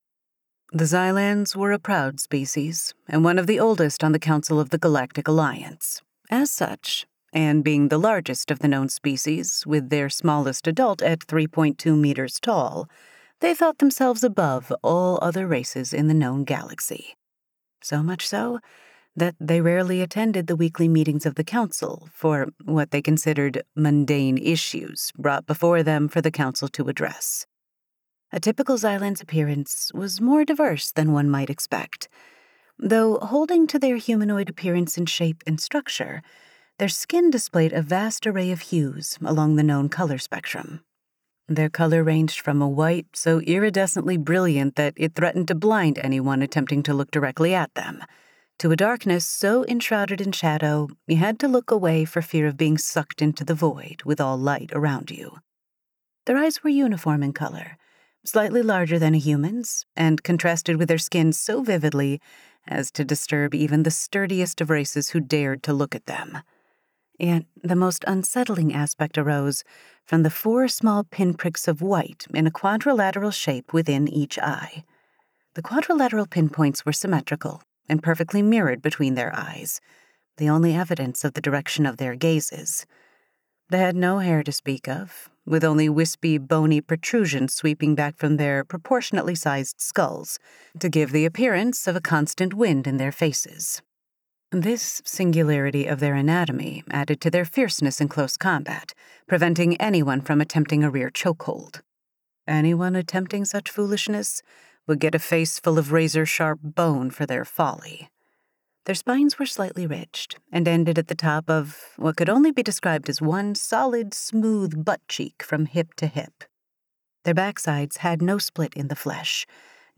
Xylan Perpetuation Audiobook